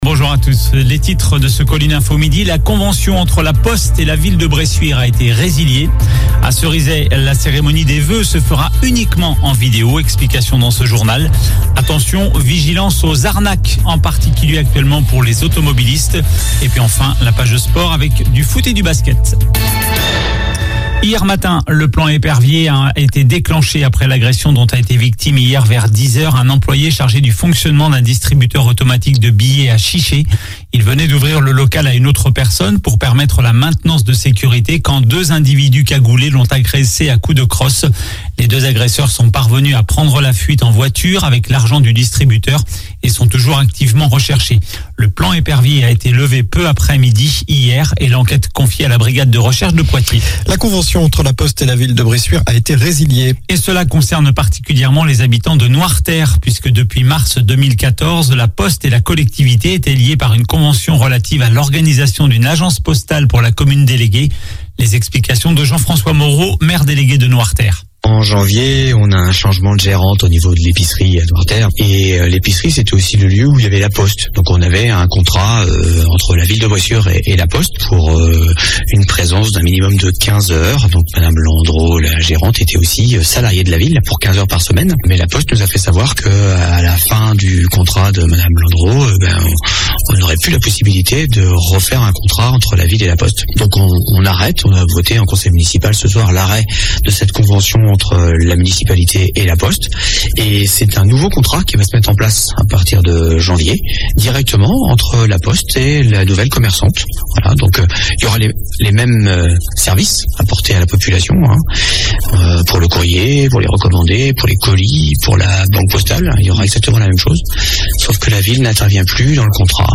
Journal du mercredi 21 décembre